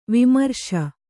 ♪ vimarśa